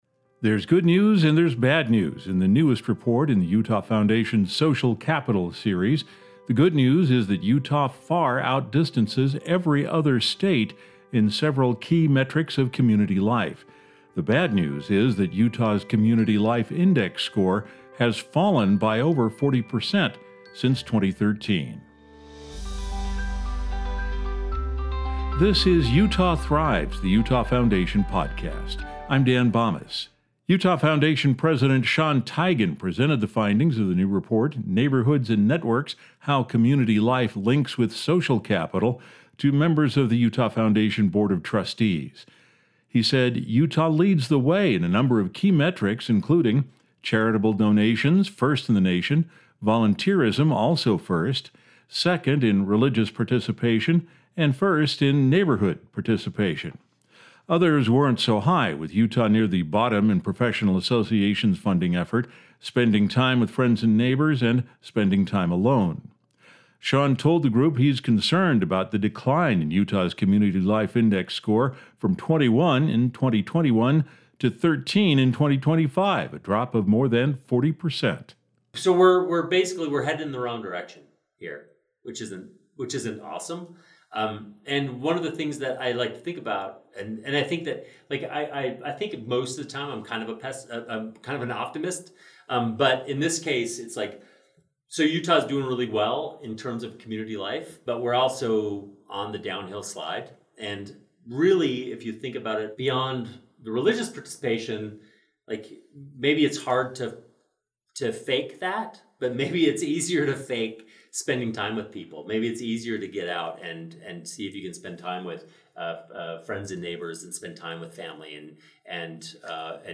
talks with Utah Foundation staff and board members